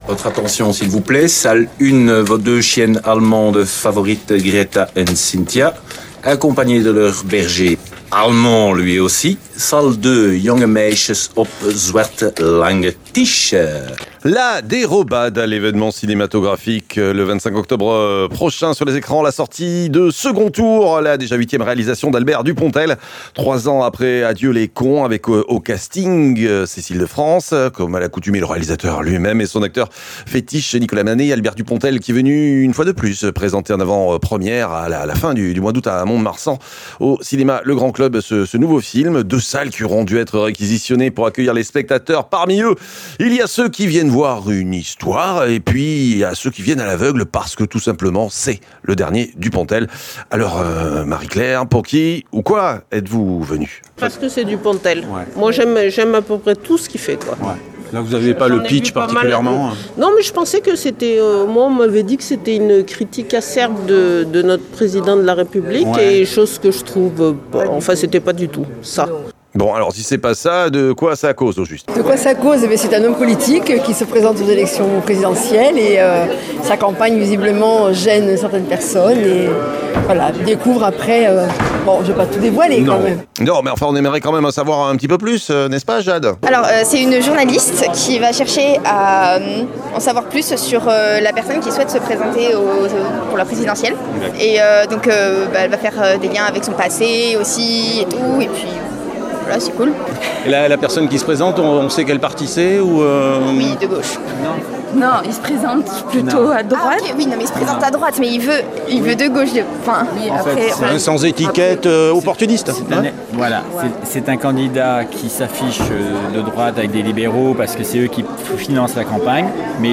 La réaction des cinéphiles à la sortie de la salle…